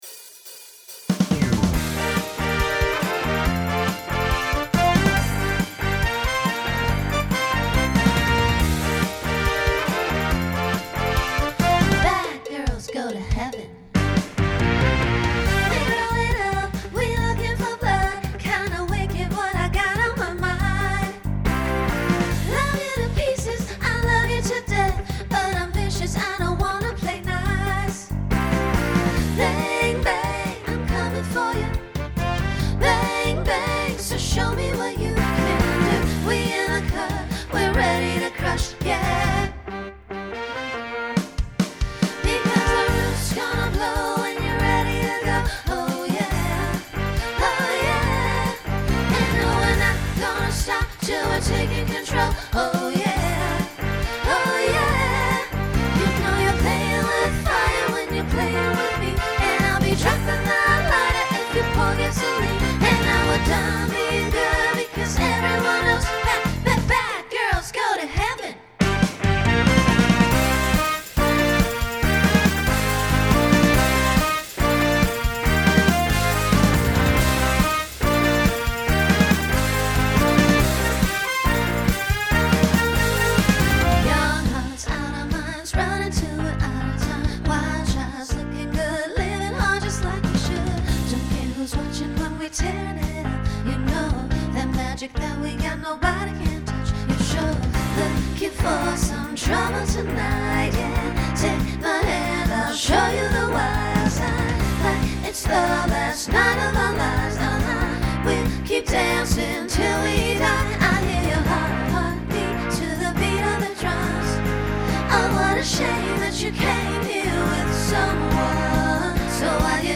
Beginning is a small group to facilitate a costume change.
Genre Pop/Dance , Rock
Transition Voicing SSA